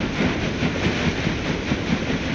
Paddle Boat Water Wash